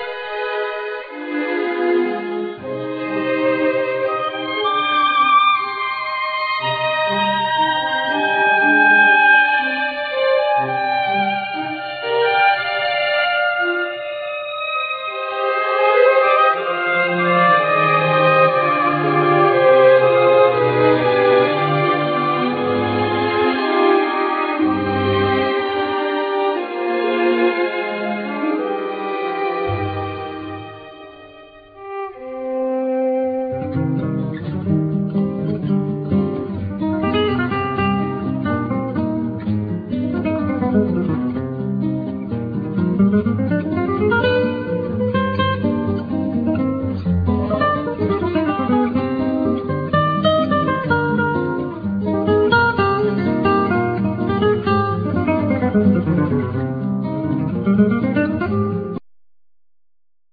Violin solo
Double bass
Accordeon
Guitar,Banjo,Melodica,Percussions